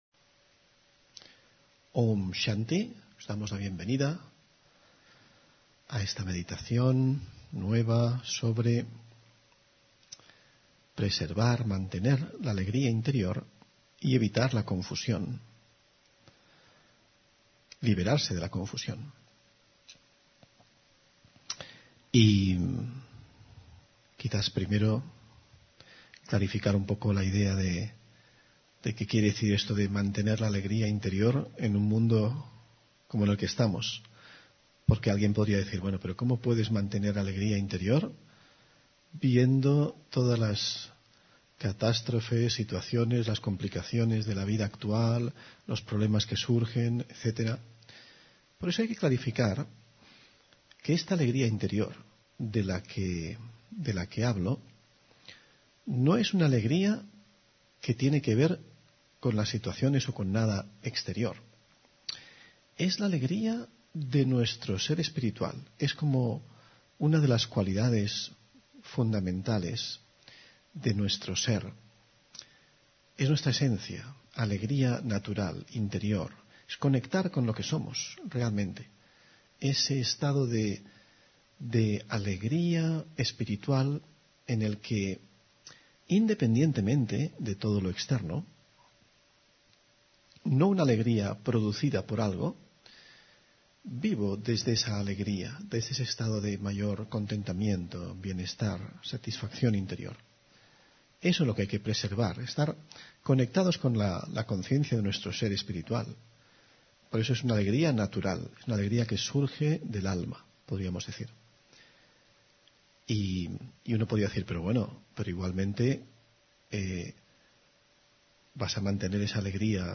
Meditación y conferencia: Permanecer alegre y libre de la confusión interior (16 Julio 2024)